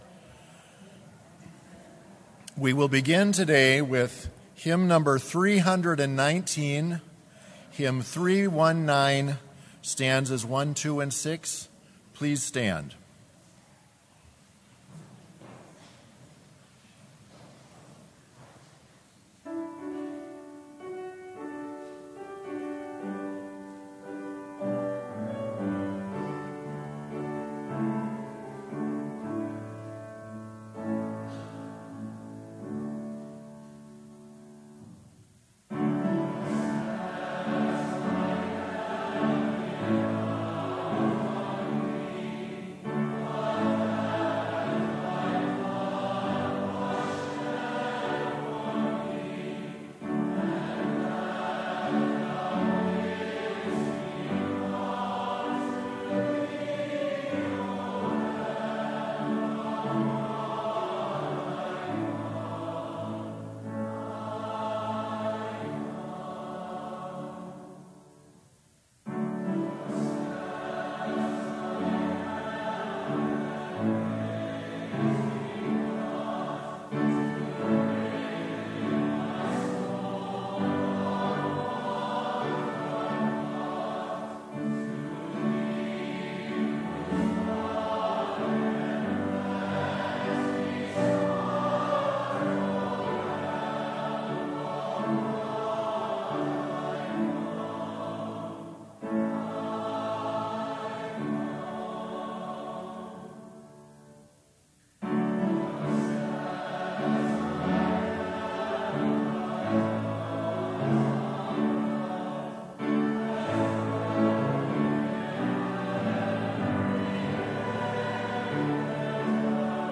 Complete service audio for Chapel - April 12, 2019